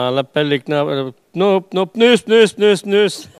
Elle crie pour appeler les canards
Catégorie Locution